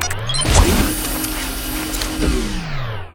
battlesuit_remove.ogg